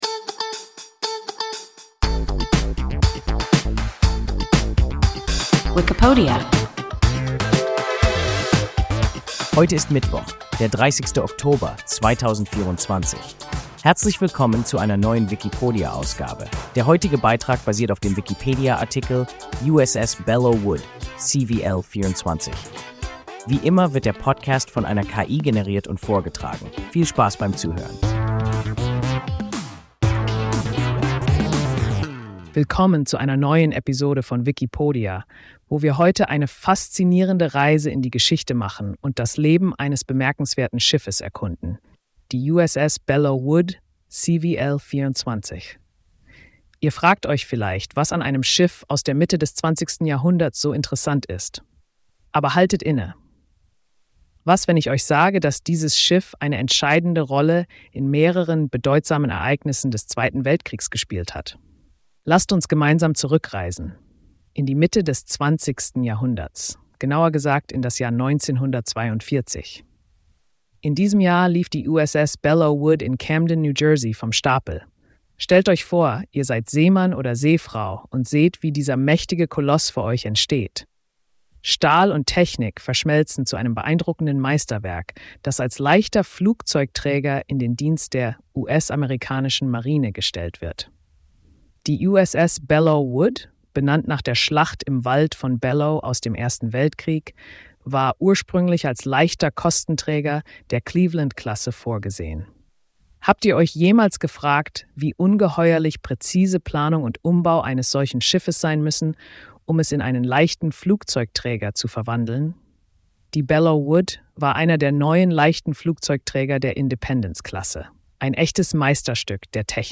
USS Belleau Wood (CVL-24) – WIKIPODIA – ein KI Podcast